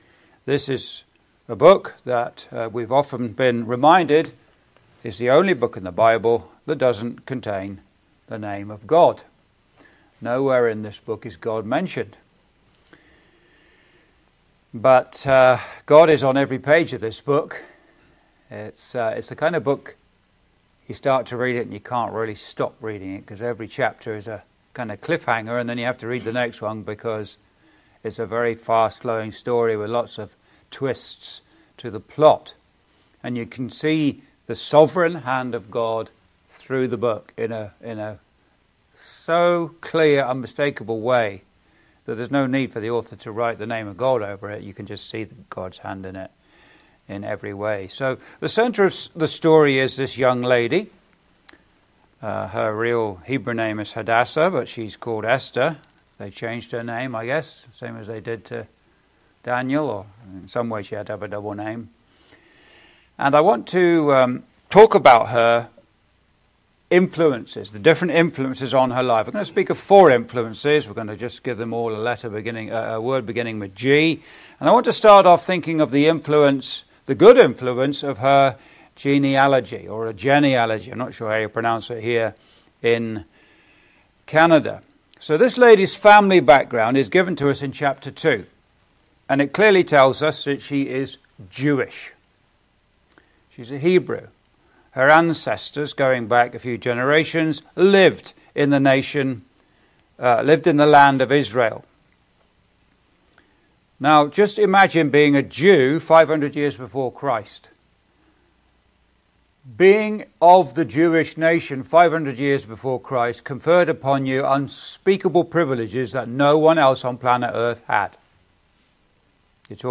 Readings: Esther 4:1-17. (Recorded in The Malden Road Gospel Hall, Windsor, ON, Canada on 12th Jan 2026) Complete series: Nehemiah - Building for God (The Sword and the Trowel) Joseph - Waiting for God
Esther